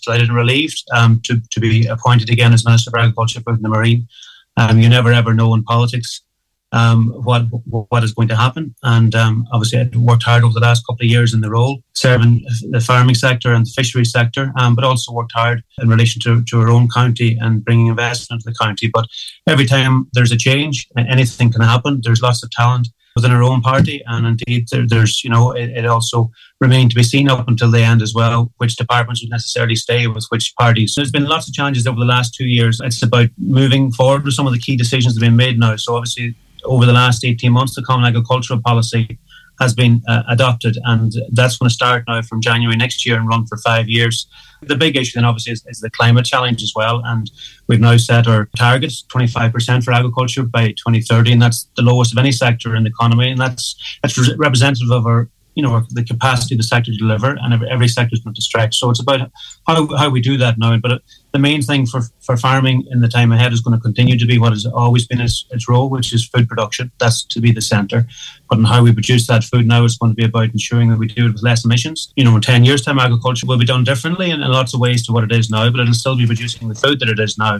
Minister Charlie McConalogue says he doesn’t underestimate the challenges that lie ahead for the farming and fisheries sector, but says he’s relieved to be staying in his department………..